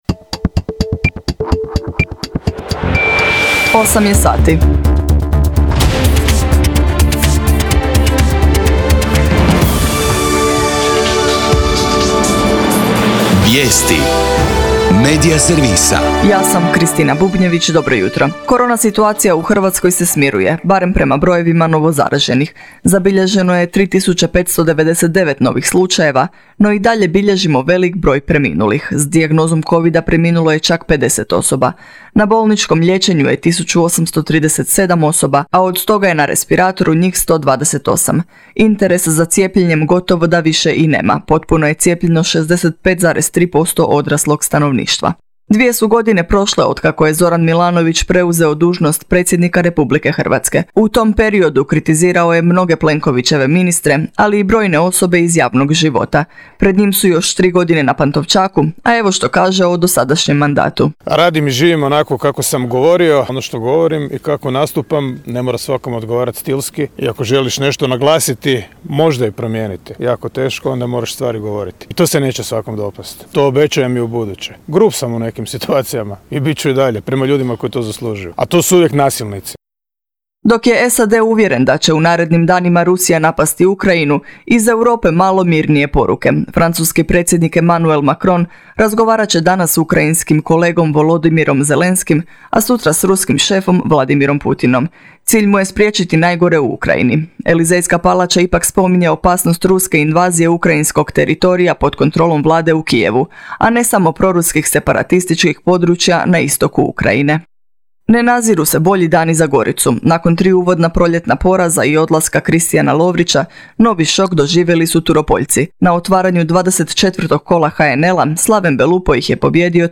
VIJESTI U 8